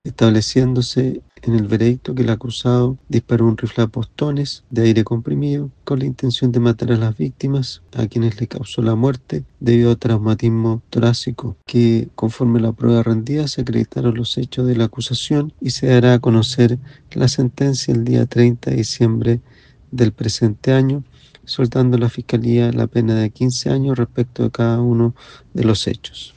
El fiscal jefe de Yungay, Mario Lobos Ortiz, recordó que están pidiendo una pena de 15 años de presidio por cada uno de los homicidios.